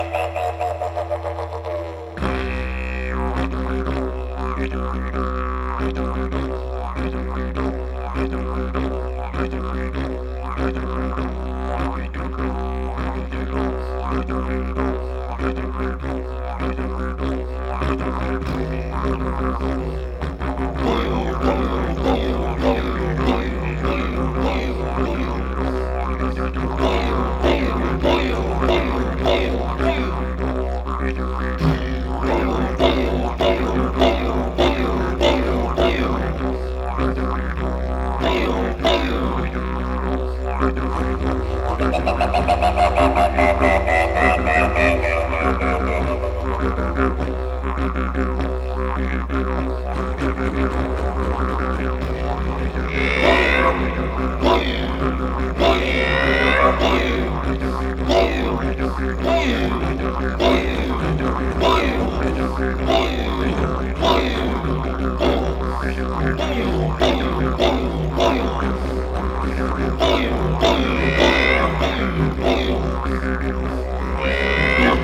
Звуки диджериду
Погрузитесь в мир аутентичных звуков диджериду — древнего духового инструмента аборигенов Австралии.